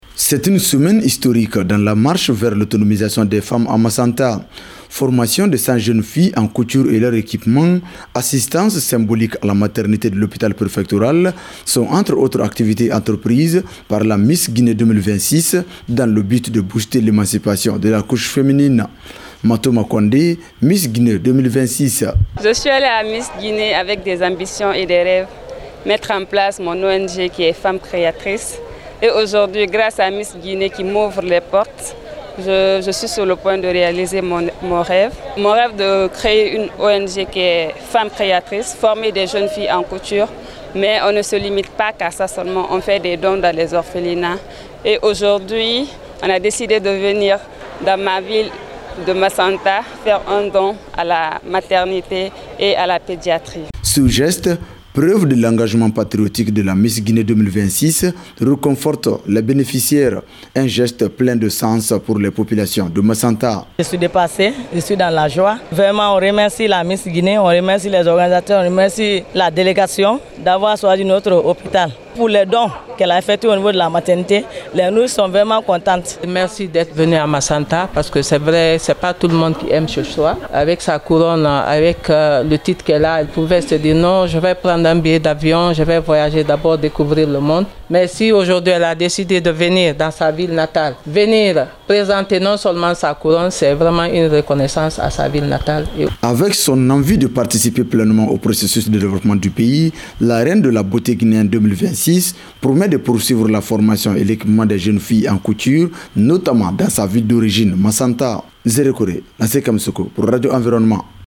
Le compte rendu de notre Correspondant Régional